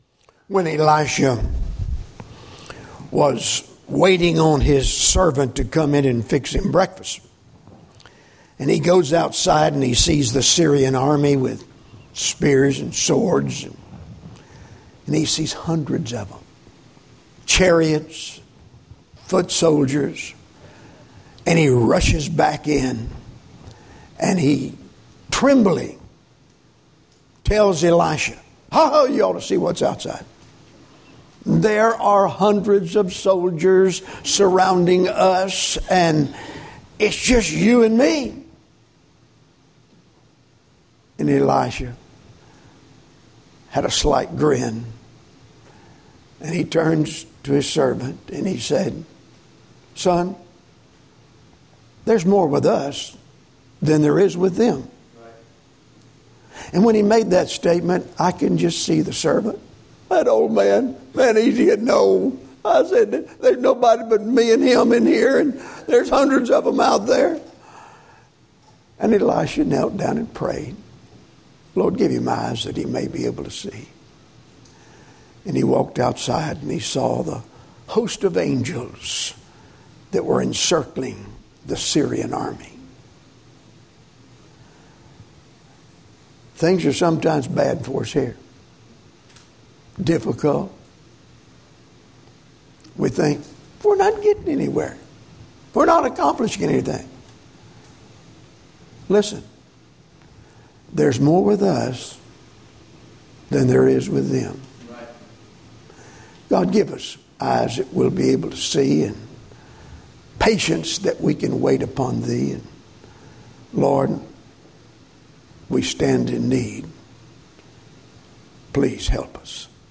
In his July 4th sermon